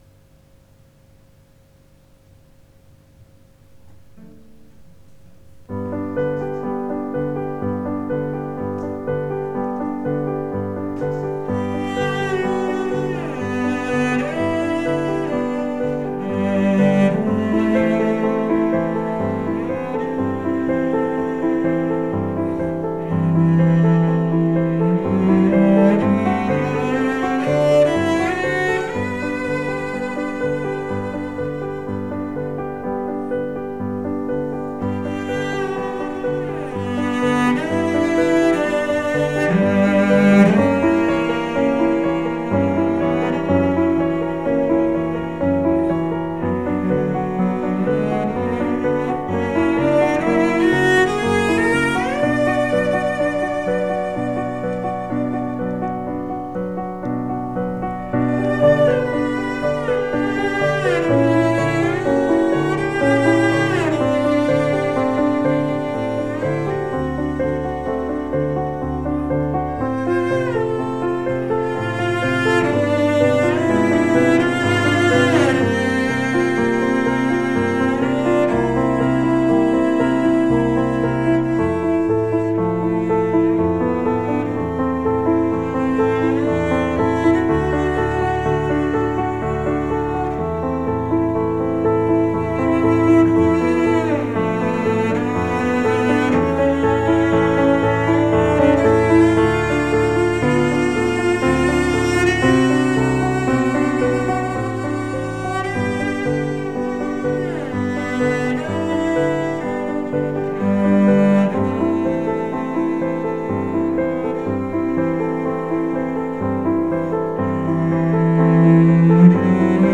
Cellist
Cello Willkommen auf meiner Seite! ich bin selbständiger Cellist in der Nähe von Lübeck und bei verschiedenen Anlässen tätig.